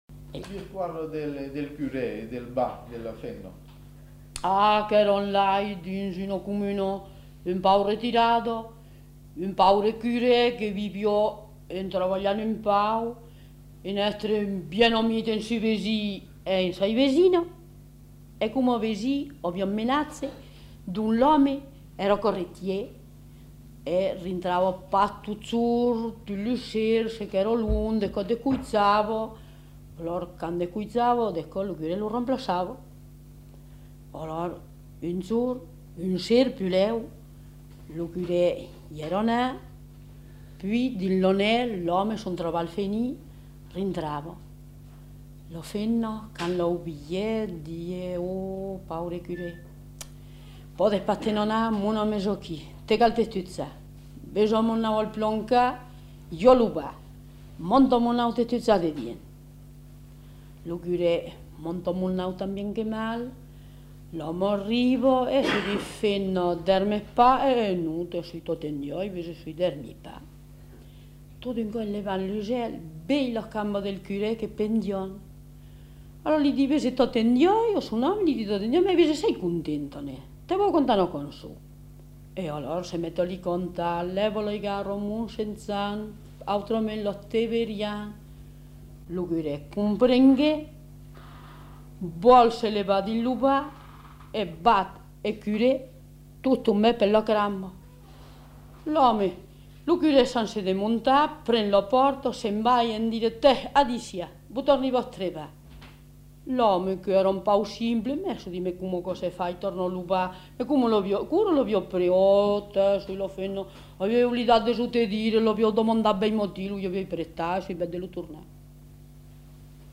Aire culturelle : Périgord
Genre : conte-légende-récit
Type de voix : voix de femme
Production du son : parlé